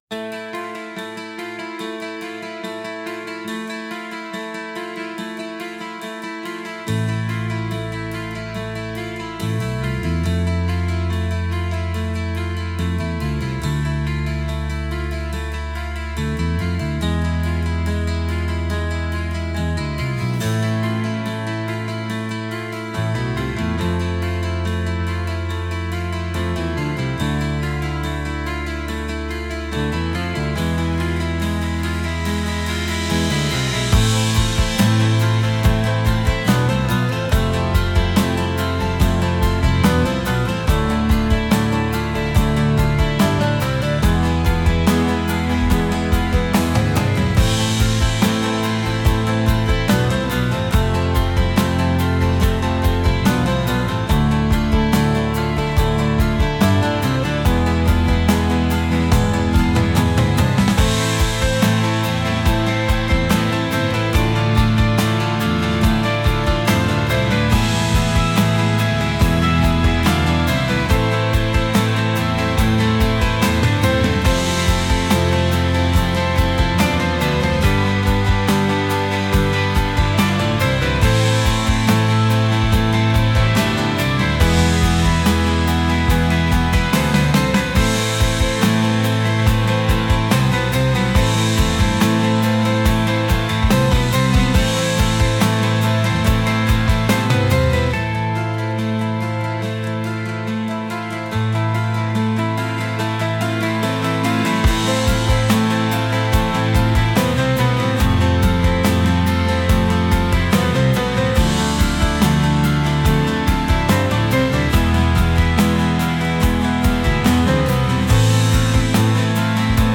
Movie, Romantic, Funk, Instrumental, Rock | 04.04.2025 17:15